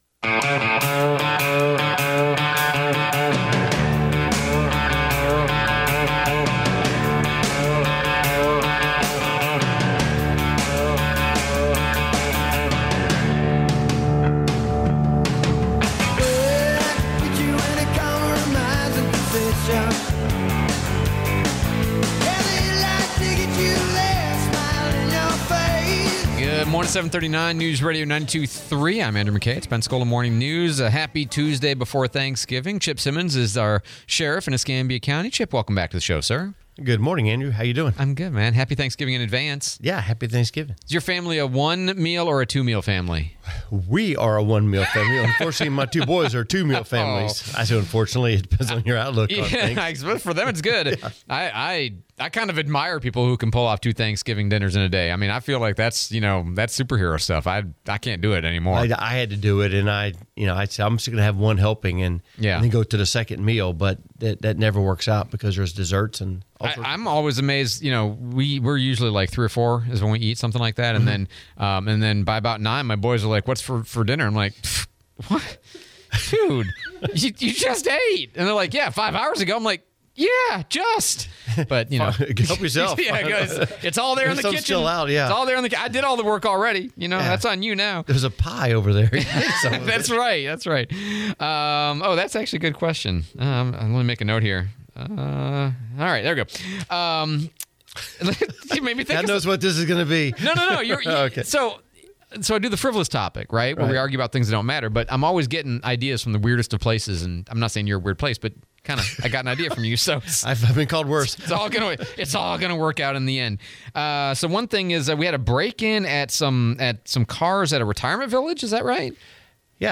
11/26/24 Sheriff Chip Simmons Interview